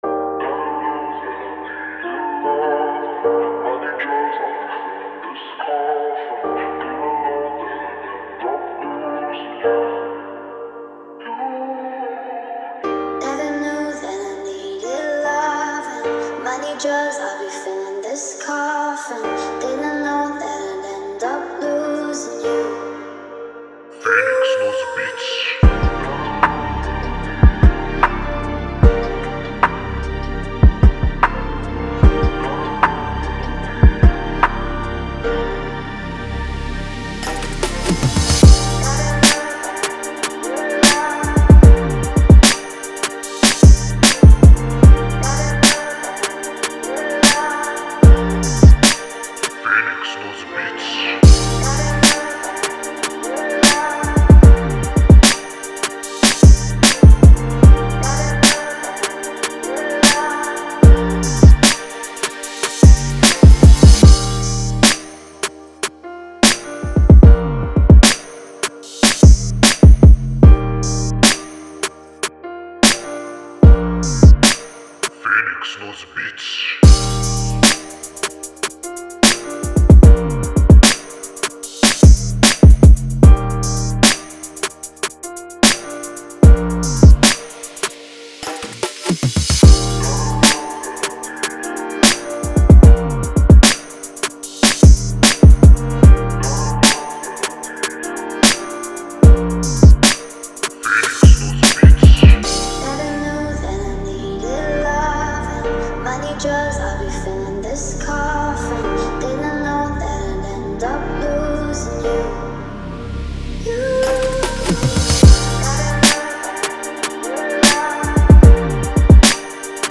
Emotional Sad RNB Instrumental